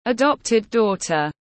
Con gái nuôi tiếng anh gọi là adopted daughter, phiên âm tiếng anh đọc là /əˈdɒp.tɪd ˈdɔː.tər/.
Adopted daughter /əˈdɒp.tɪd ˈdɔː.tər/